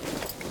tac_gear_14.ogg